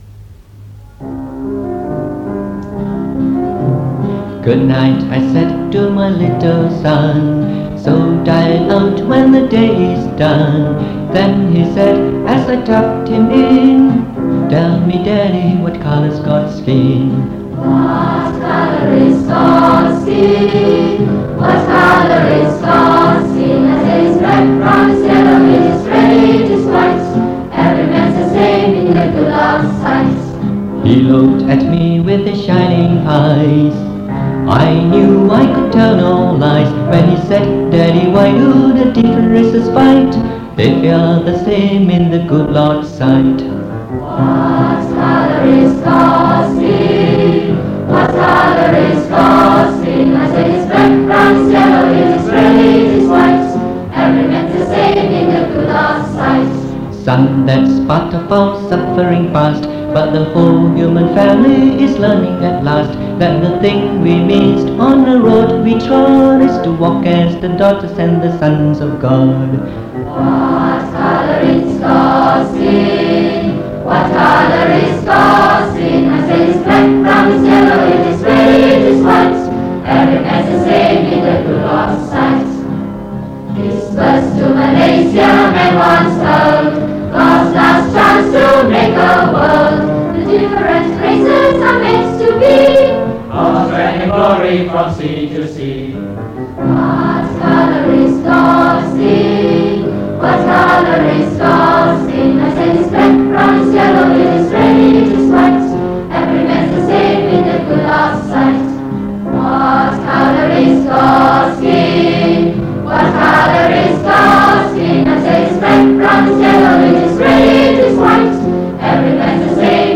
This is the final part of the Singout - a choral festival hosted by Methodist Girls' School (MGS) back in 1968. Together with the students of MGS, a few other schools also joined in to make up a 80-member strong choir.